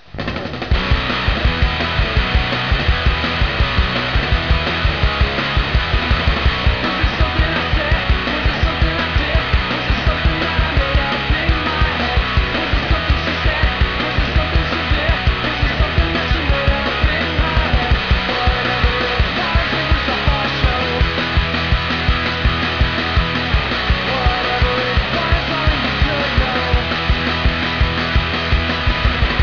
All sounds are in 8 bit mono.